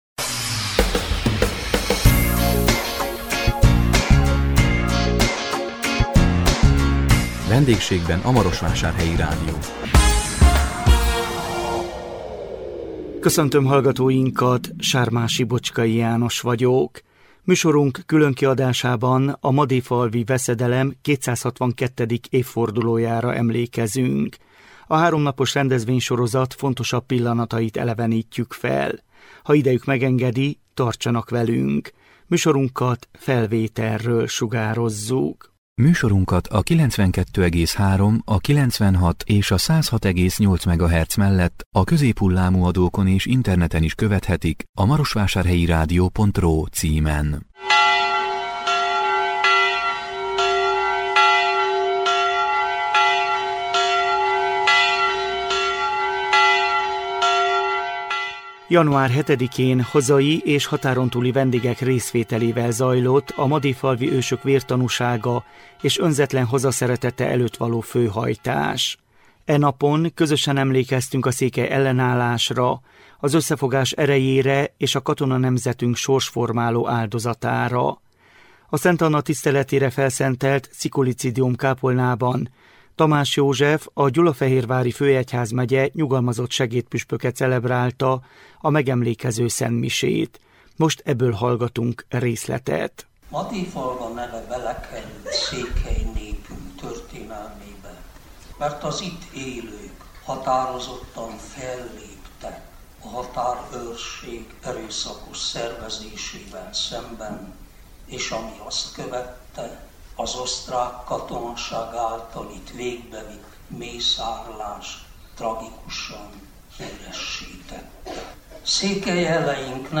A 2026 január 8-án közvetített VENDÉGSÉGBEN A MAROSVÁSÁRHELYI RÁDIÓ című műsorunk különkiadásában a madéfalvi veszedelem 262. évfordulójára emlékeztünk. A háromnapos rendezvénysorozat fontosabb pillanatait elevenítetük fel.